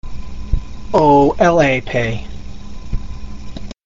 OLAP pronunciation sound file
The OLAP Surgeons (pronounced oh-el-aa-pay), come from a galaxy far older and further away than humanity's. These beings were present at the very beginnings of the physical universe and, for a time, have remained dormant, until certain eras and individuals were ready to use them again.